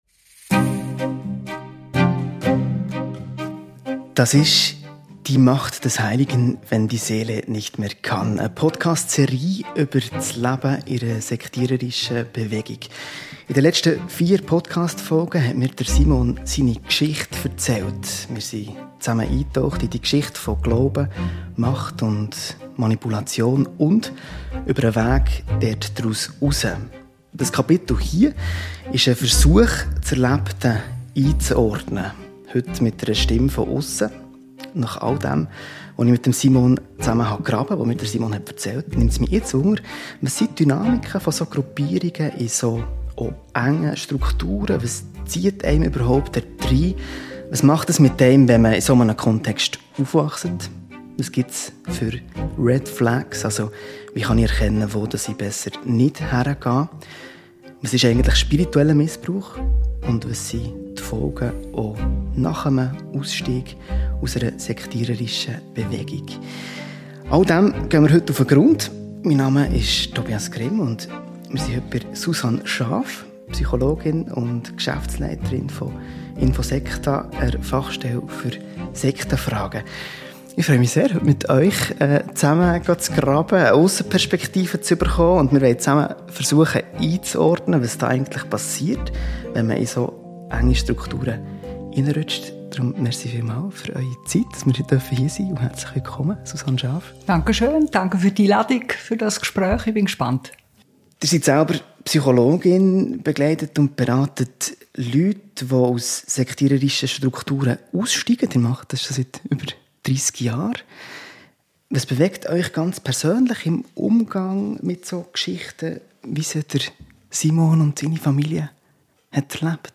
Kapitel 5: Fachgespräch mit infoSekta ~ Die Macht des Heiligen – wenn die Seele nicht mehr kann Podcast